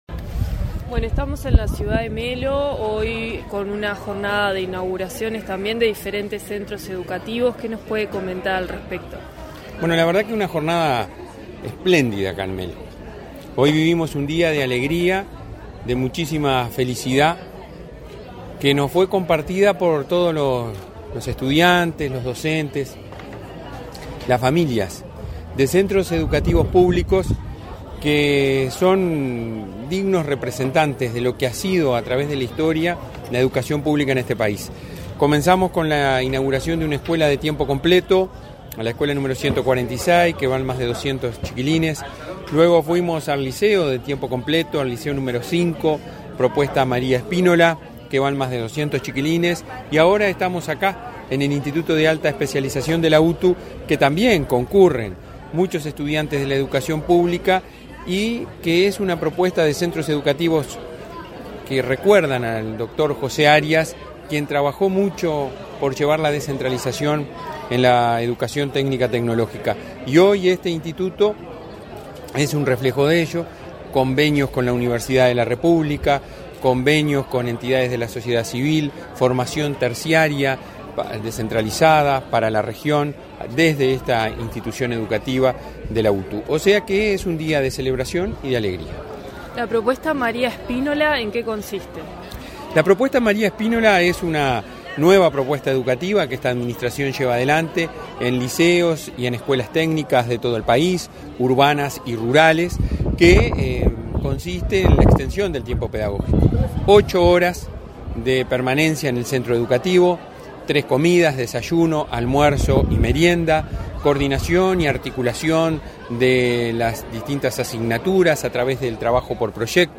Entrevista al presidente de la ANEP, Robert Silva
Entrevista al presidente de la ANEP, Robert Silva 04/11/2022 Compartir Facebook X Copiar enlace WhatsApp LinkedIn La Administración Nacional de Educación Pública (ANEP) inauguró tres centros educativos en Cerro Largo, este 4 de noviembre, con la presencia de su presidente, Robert Silva. Tras los eventos, el jerarca realizó declaraciones a Comunicación Presidencial.